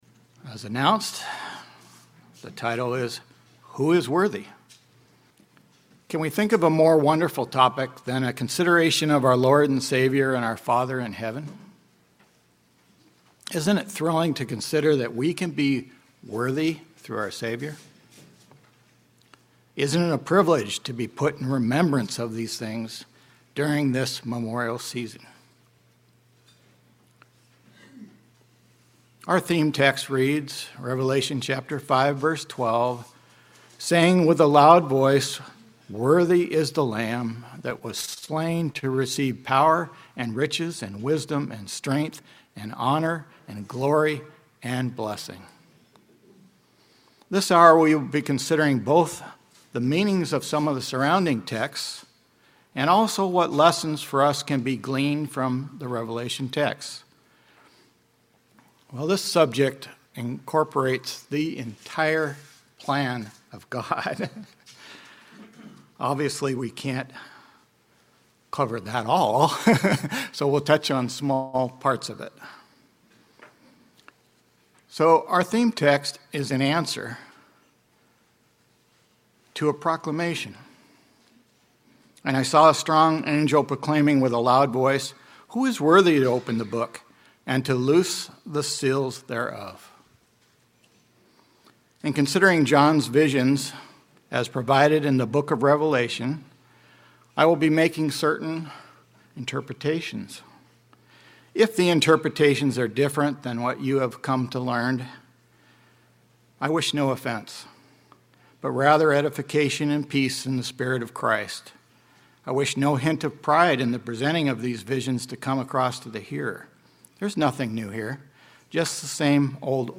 Series: 2026 Wilmington Convention